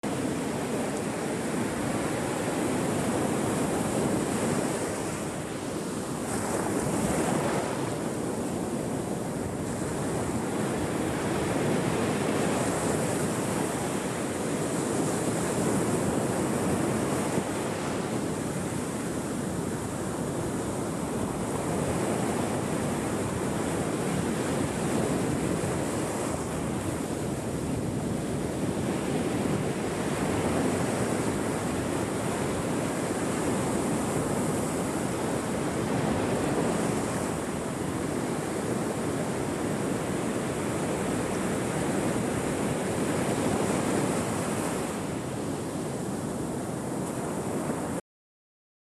Ocean waves, Relaxing Waves, Tranquil Geo
Category 🌿 Nature
Geo Nature Ocean Relaxing Sounds Tranquil Water waves sound effect free sound royalty free Nature